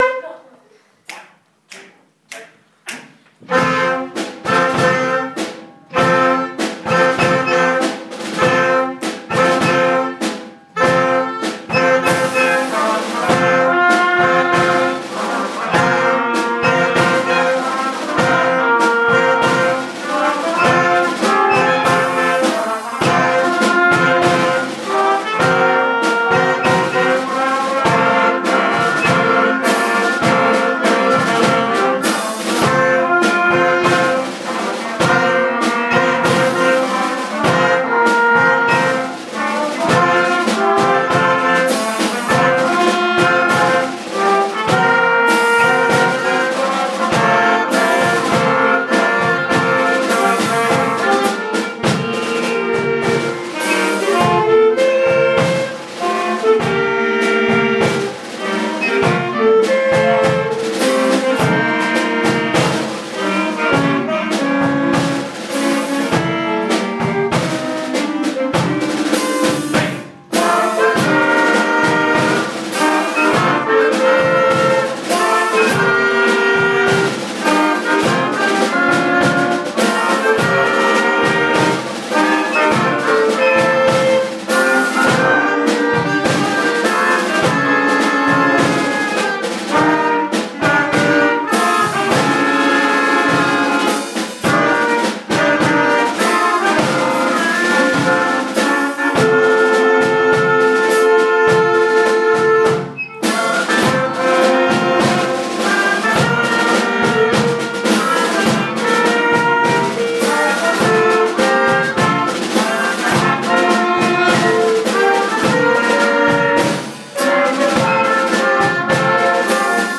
Primary Wind Band